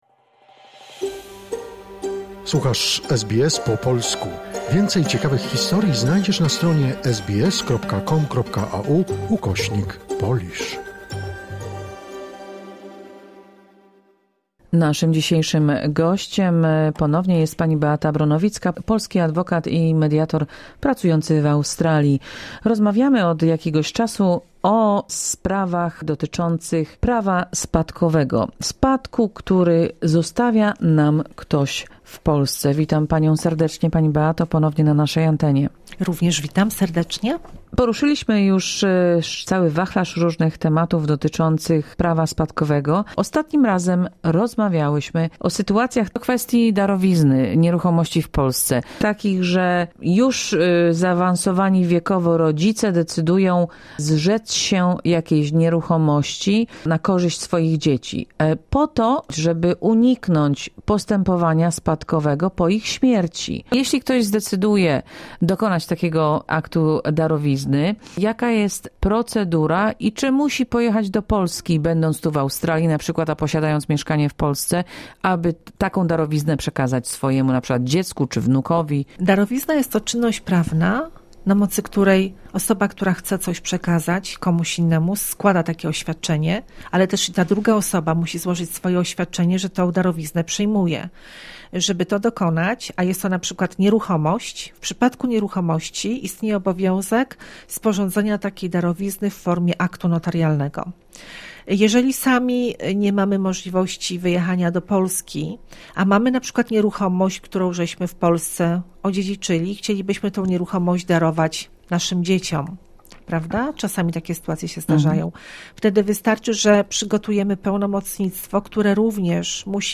W cyklu rozmów o spadkach i dziedziczeniu majątku pozostawionego w Polsce